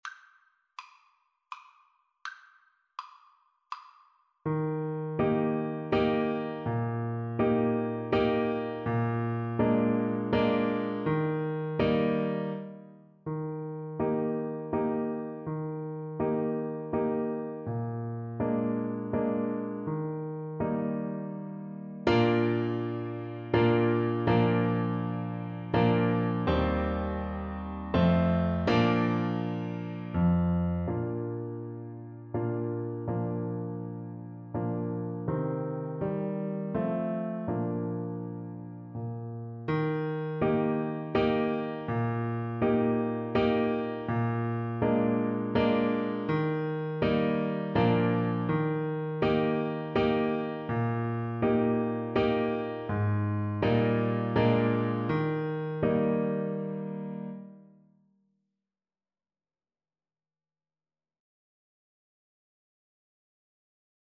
Clarinet
Allegro = c. 112 (View more music marked Allegro)
3/4 (View more 3/4 Music)
Eb major (Sounding Pitch) F major (Clarinet in Bb) (View more Eb major Music for Clarinet )
Traditional (View more Traditional Clarinet Music)